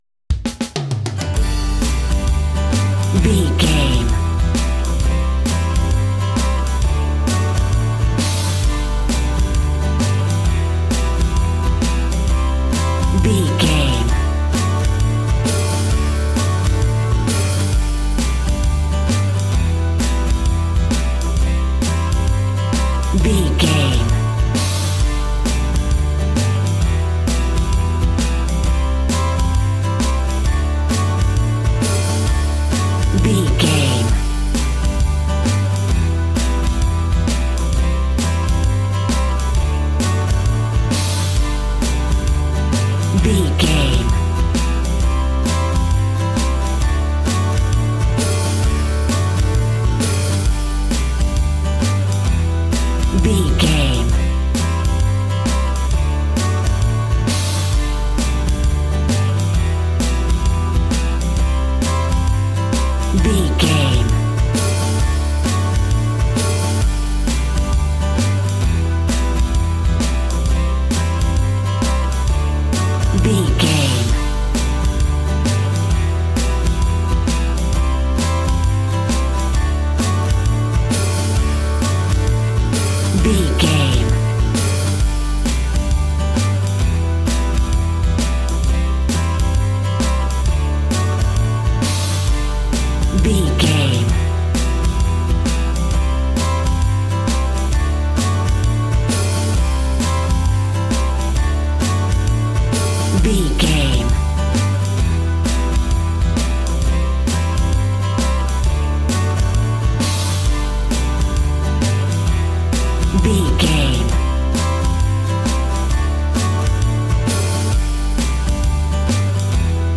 A piece of old country music straight from the 50s!
Ionian/Major
country
blues
folk
country guitar
acoustic guitar
bass guitar
drums
hammond organ